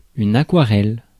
Ääntäminen
UK RP : IPA : /ˈwɔ.tɜ.ˌkʌ.lɜ/